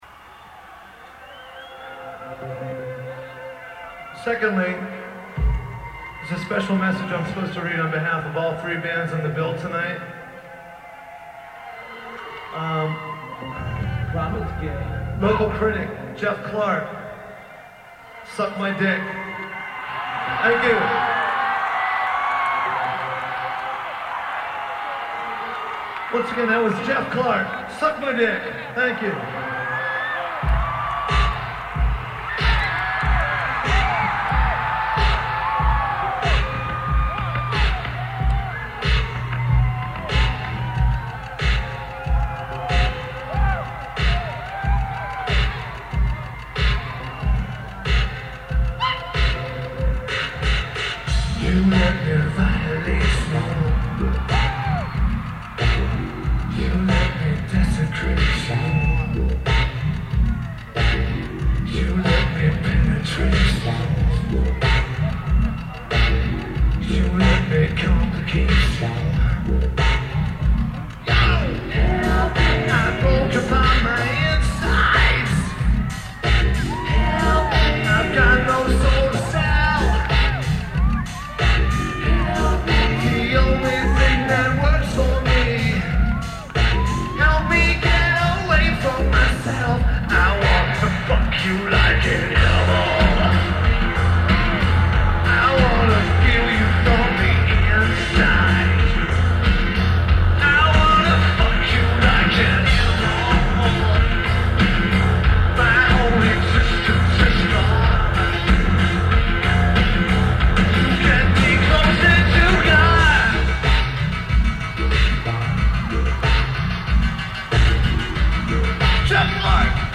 Atlanta, GA United States